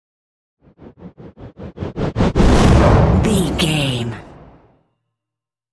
Dramatic whoosh to hit trailer
Sound Effects
Fast paced
In-crescendo
Atonal
intense
tension
woosh to hit